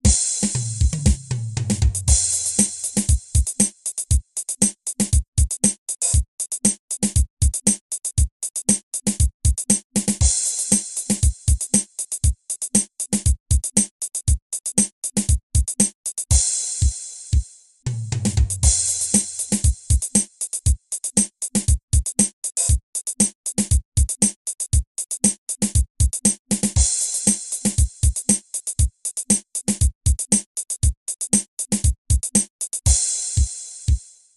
ドラムのみで構成された音楽。